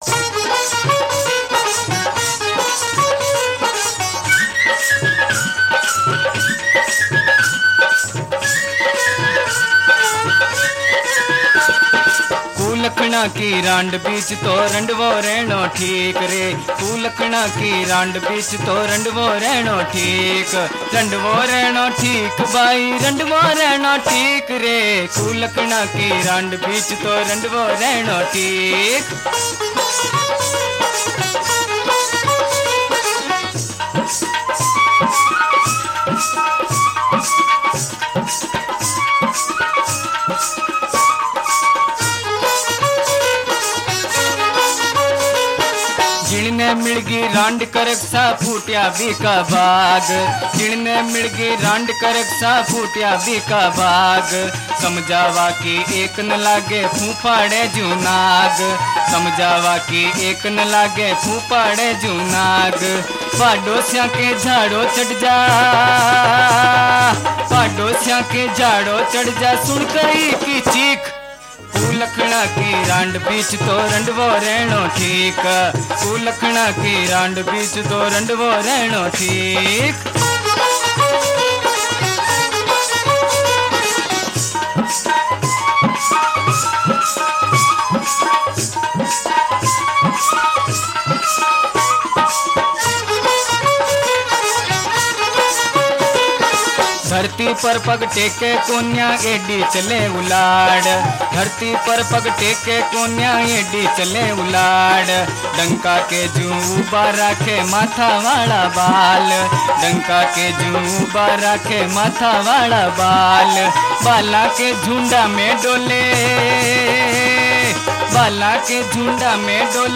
Rajasthani Songs
(Live)